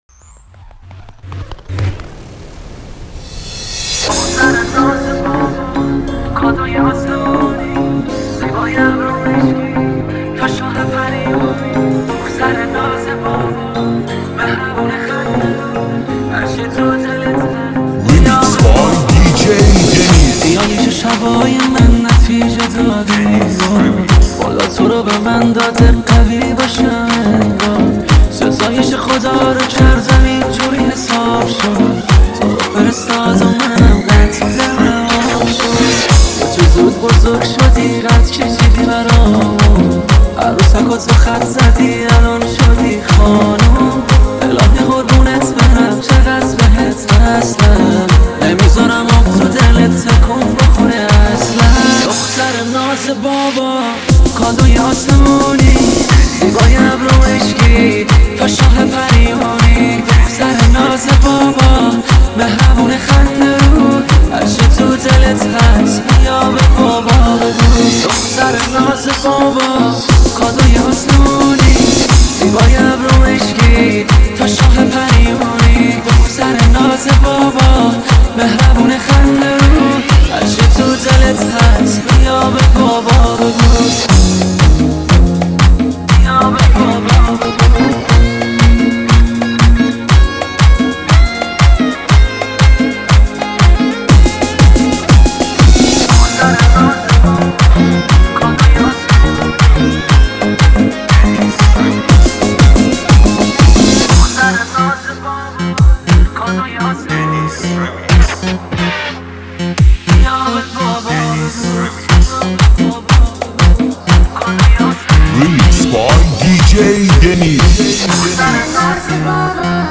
ریمیکس
شاد